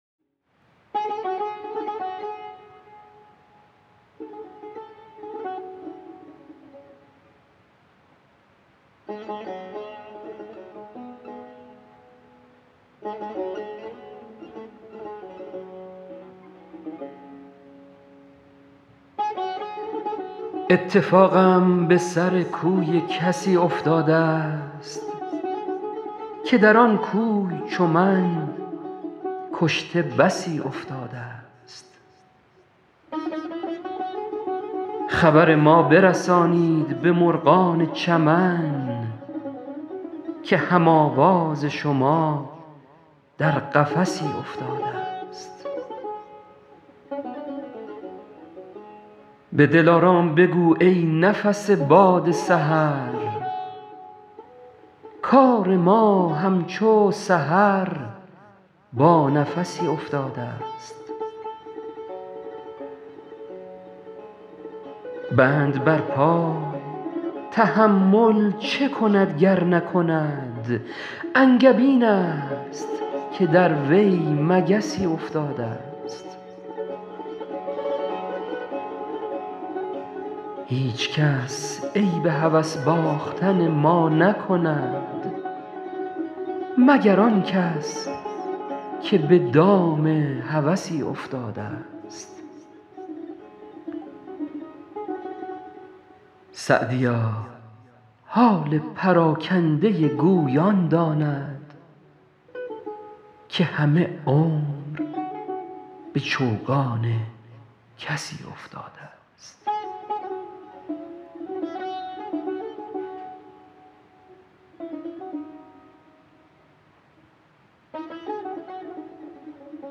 سعدی » دیوان اشعار » غزلیات » غزل شمارهٔ ۵۸ با خوانش